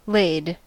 Ääntäminen
Synonyymit layed Ääntäminen US : IPA : [leɪd] Haettu sana löytyi näillä lähdekielillä: englanti Käännöksiä ei löytynyt valitulle kohdekielelle.